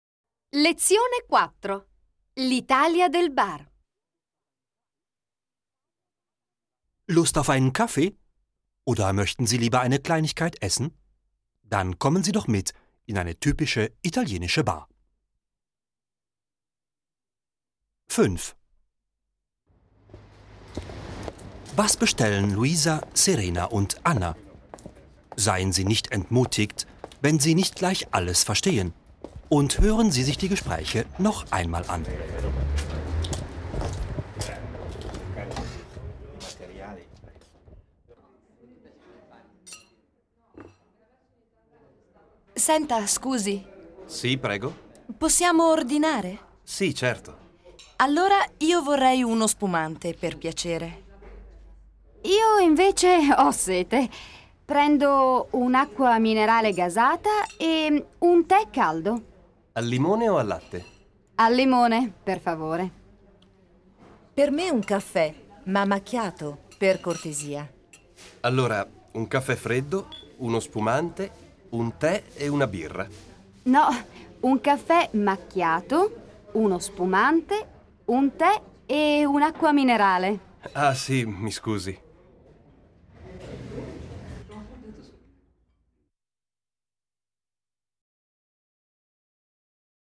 Audio ist Trumpf: Das Set enthält 6 Audio-CDs, die den Kurs mit den Lektionstexten, alltagsnahen Hörspielen und Übungen begleiten